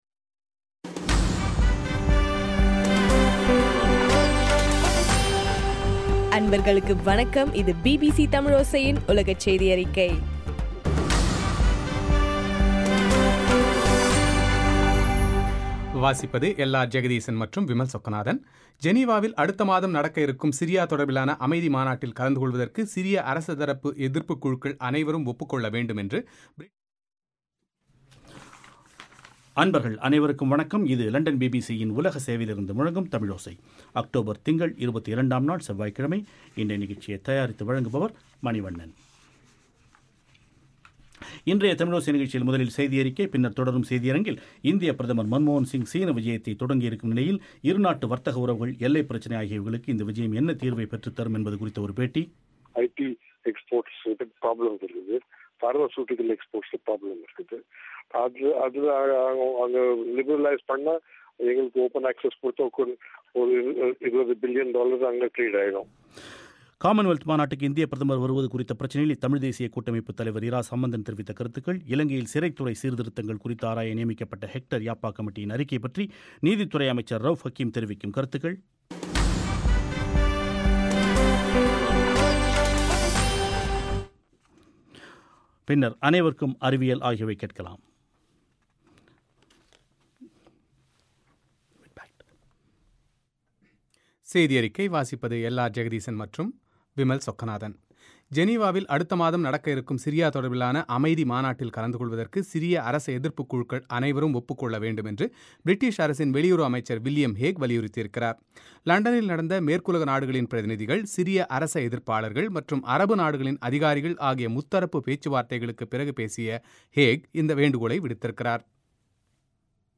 இன்றைய தமிழோசை நிகழ்ச்சியில் இந்திய ப் பிரதமர் மன்மோஹன் சிங் சீன விஜயத்தை தொடங்கியிருக்கும் நிலையில், இரு நாட்டு வர்த்தக உறவுகள், எல்லைப் பிரச்சினை ஆகியவைகளுக்கு இந்த விஜயம் என்ன தீர்வைப் பெற்றுத்தரும் என்பதுகுறித்த ஒரு பேட்டி